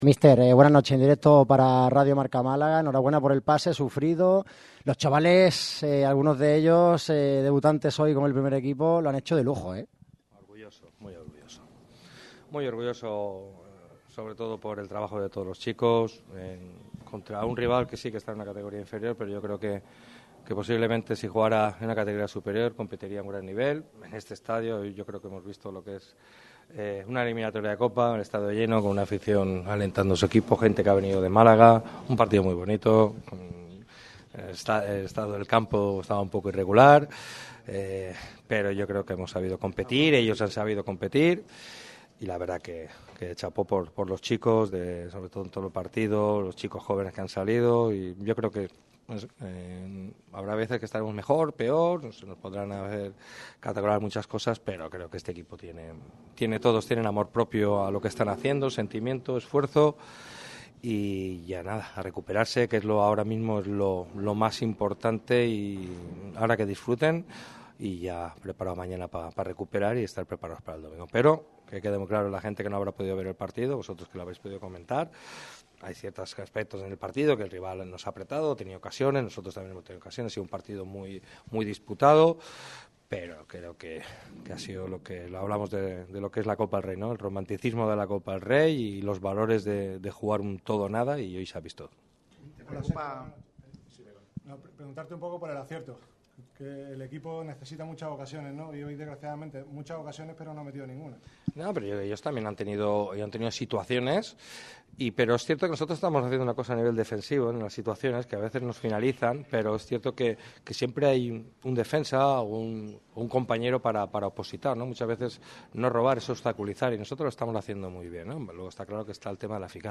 rueda de prensa de postpartido